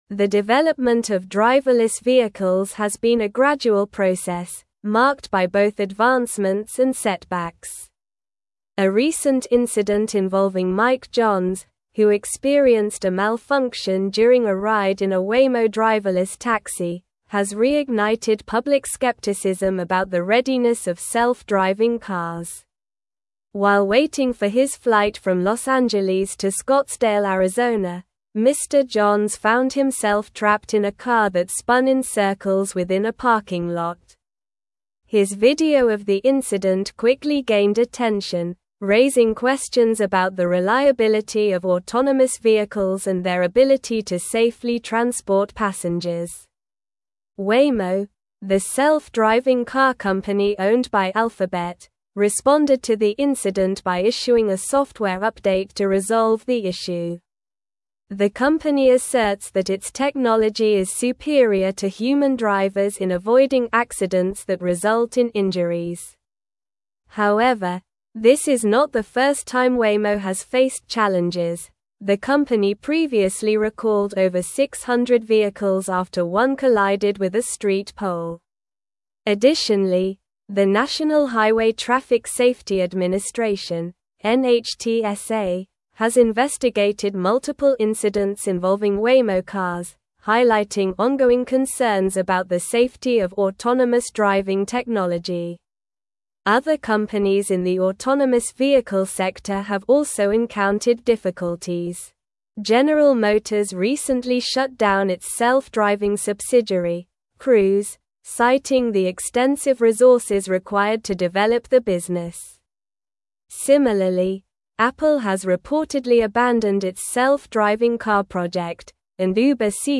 Slow
English-Newsroom-Advanced-SLOW-Reading-Challenges-Persist-in-the-Adoption-of-Driverless-Vehicles.mp3